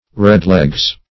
Search Result for " redlegs" : The Collaborative International Dictionary of English v.0.48: Redleg \Red"leg`\ (r?d"l?g`), Redlegs \Red`legs`\ (-l?gz`), n. (Zool.)